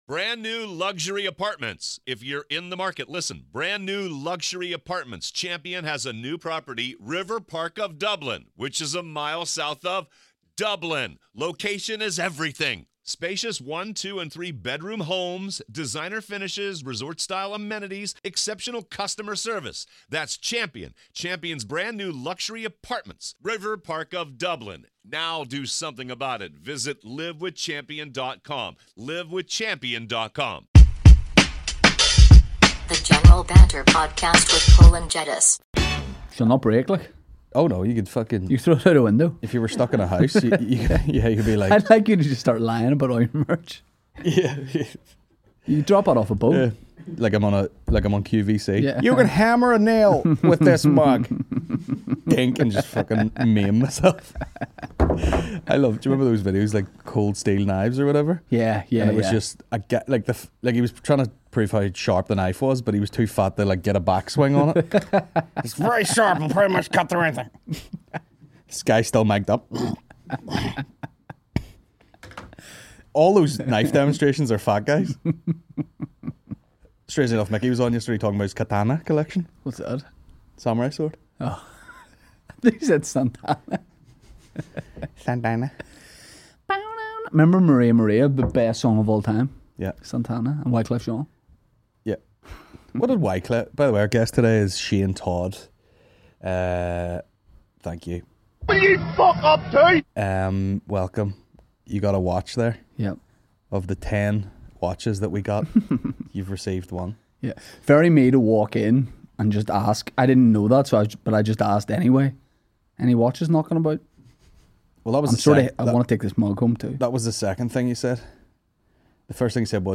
Comedy podcast